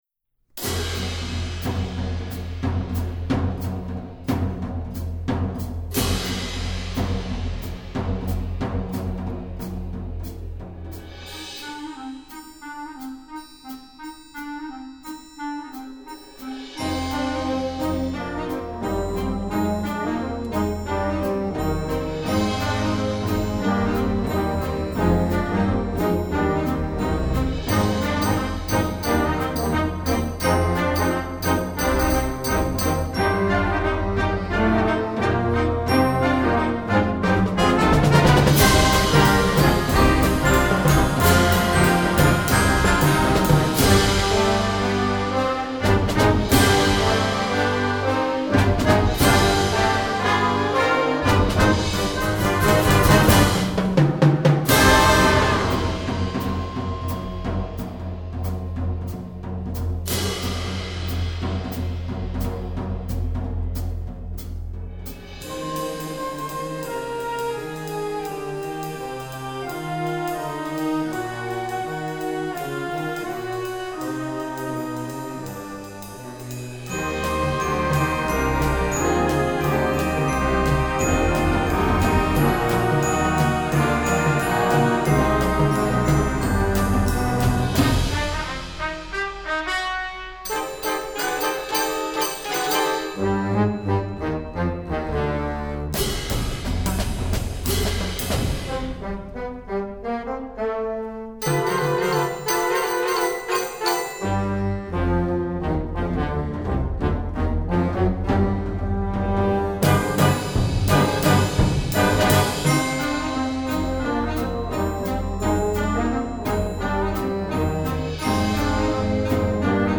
secular, swing